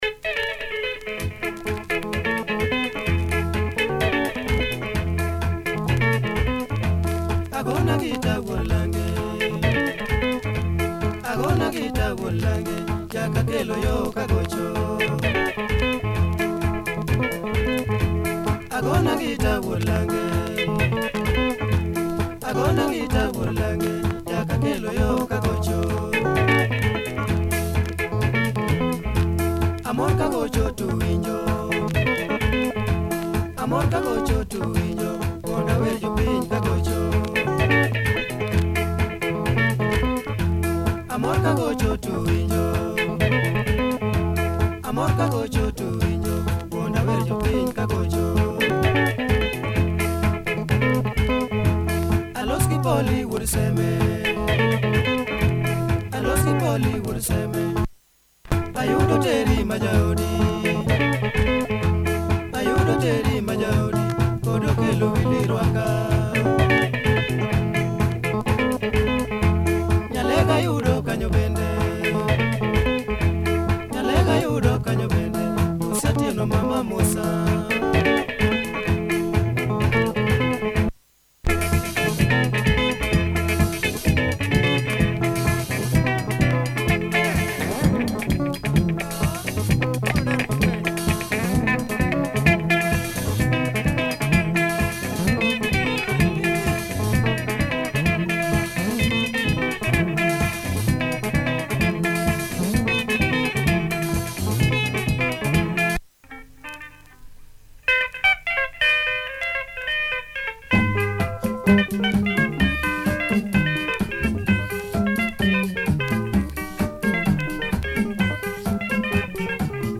Good early LUO benga from 1974, spacey breakdown!